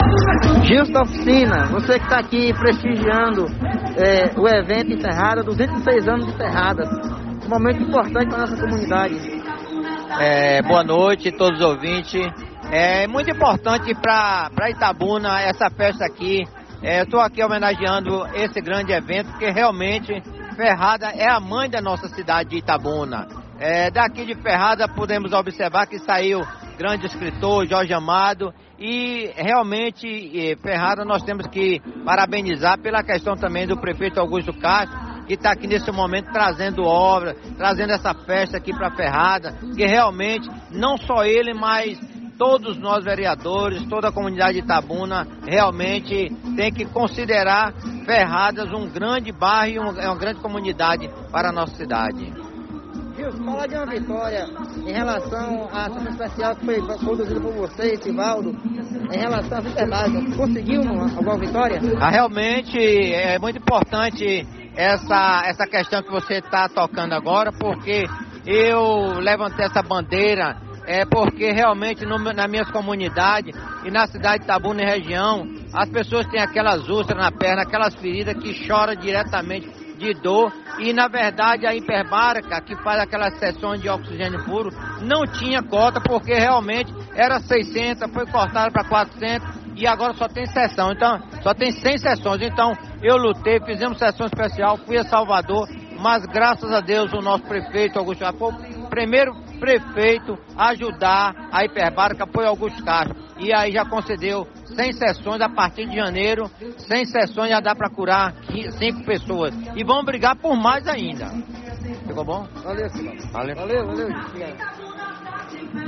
Os vereadores Sivaldo Reis e Gilson da Oficina, ambos do PL, participaram das festividades em comemoração do aniversário de Ferradas, nesta quarta-feira (27). Durante entrevista, os edis também destacaram a vitória conquistada após sessão especial, que cobrou a ampliação de sessões para tratamento contra feridas crônicas.